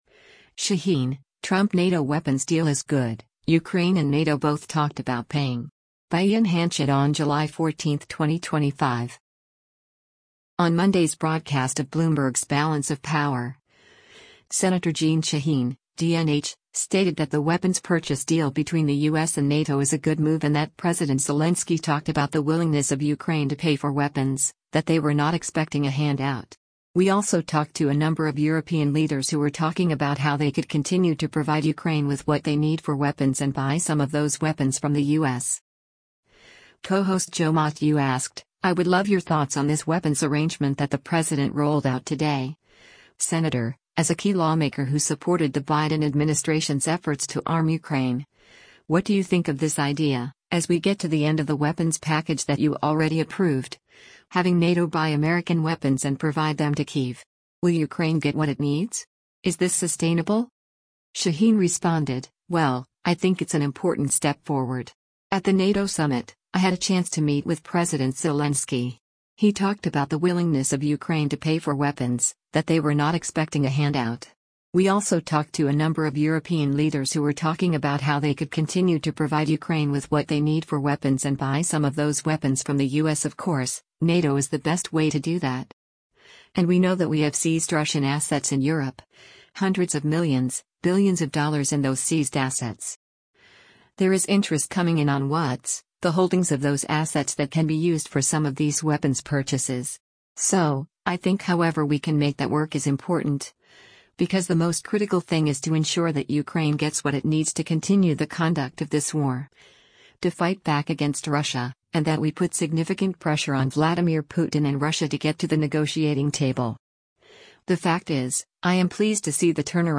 On Monday’s broadcast of Bloomberg’s “Balance of Power,” Sen. Jeanne Shaheen (D-NH) stated that the weapons purchase deal between the U.S. and NATO is a good move and that President Zelenskyy “talked about the willingness of Ukraine to pay for weapons, that they were not expecting a hand out.